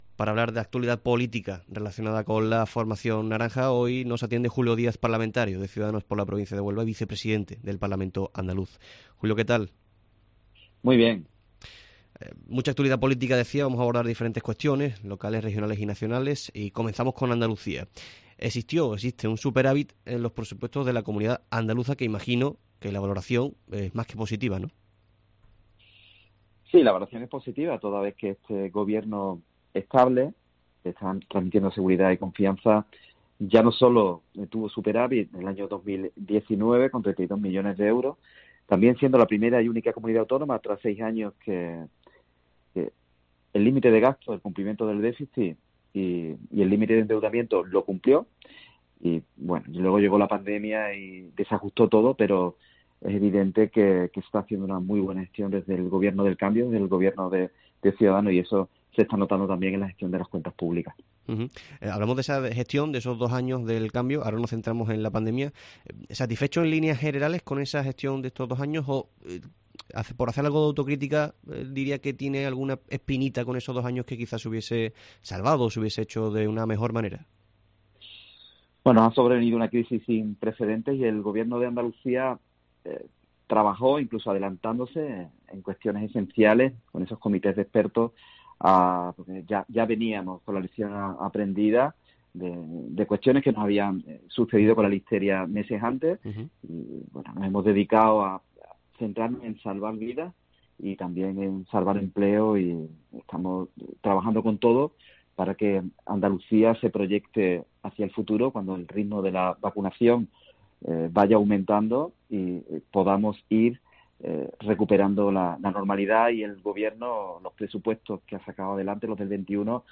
El parlamentario andaluz de Ciudadanos por Huelva y vicepresidente del Parlamento de Andalucía, Julio Díaz, ha atendido a COPE Huelva para analizar...
Precisamente desde el despacho de este organismo regional descuelga el teléfono y contesta a nuestras preguntas.